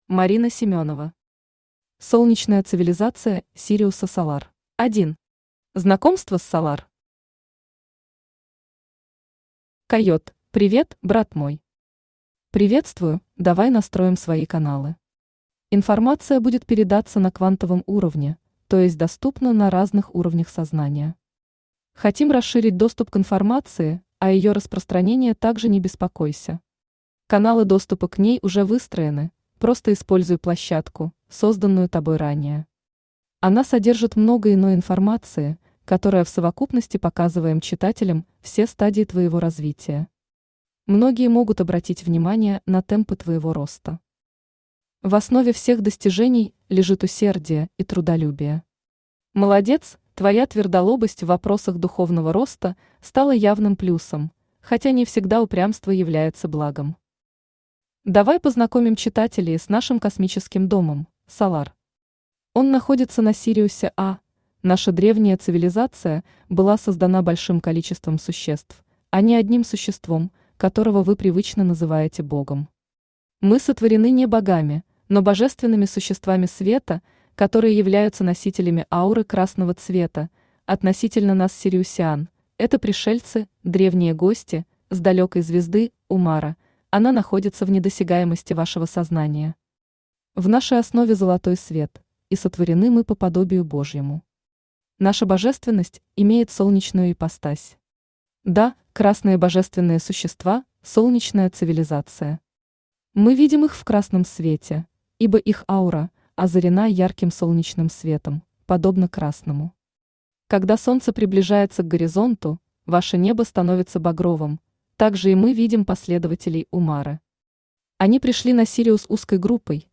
Аудиокнига Солнечная цивилизация Сириуса Солар | Библиотека аудиокниг
Aудиокнига Солнечная цивилизация Сириуса Солар Автор Марина Семенова Читает аудиокнигу Авточтец ЛитРес.